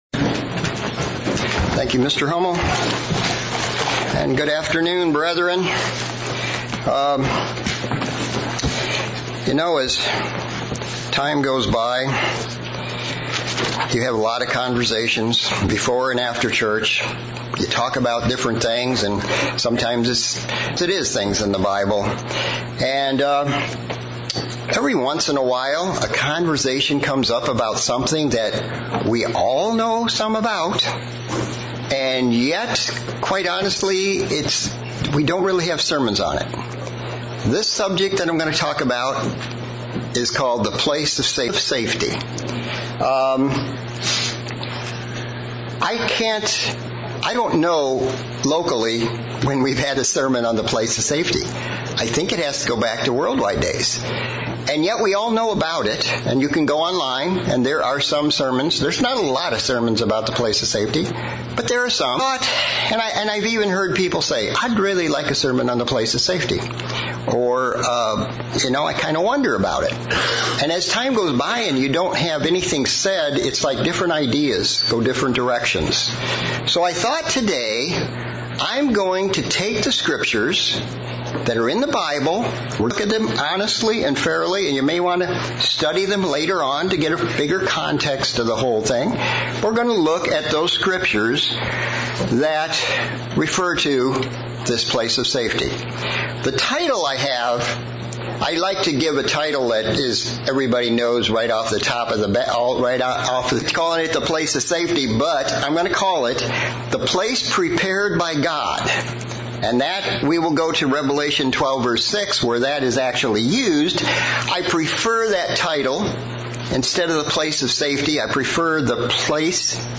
Sermon looking at the scritpures about the place of safety. What is the Place of Safety? Where is the Place of safety?